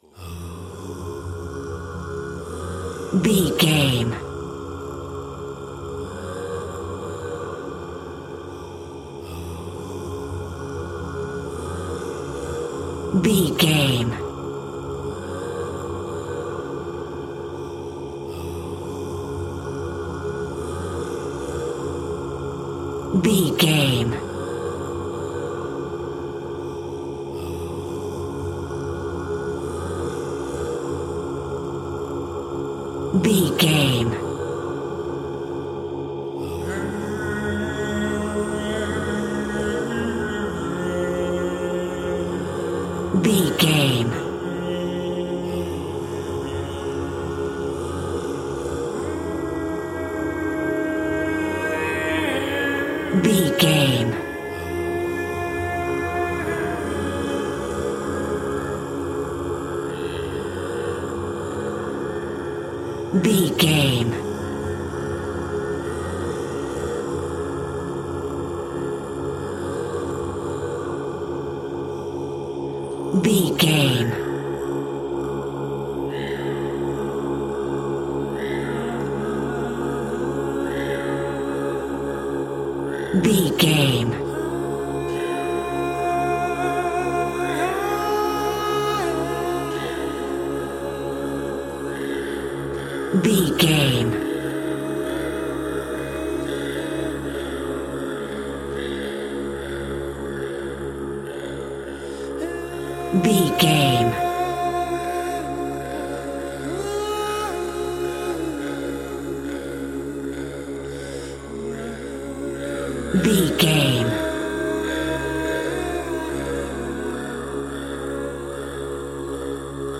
Ionian/Major
D♭
groovy
inspirational